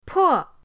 po4.mp3